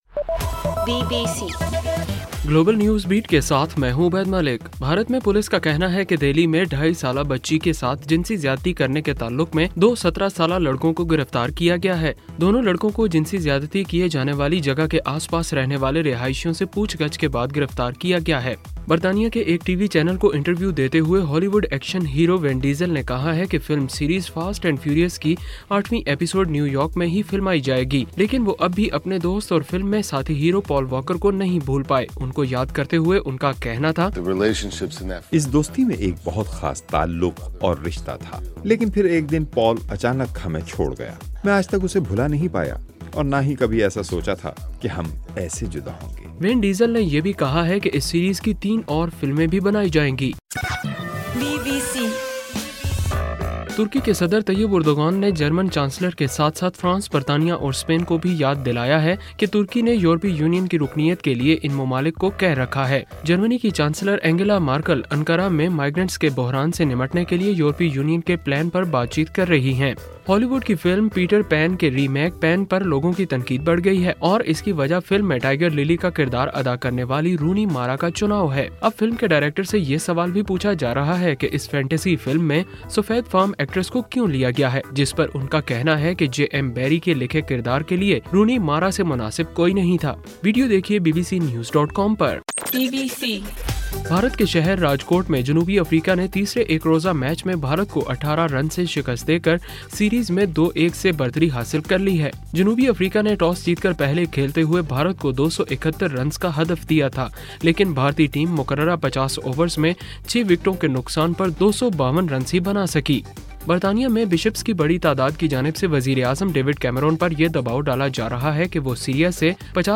اکتوبر 18: رات 11 بجے کا گلوبل نیوز بیٹ بُلیٹن